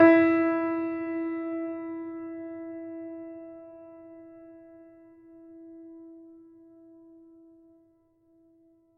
Steinway_Grand
e3.mp3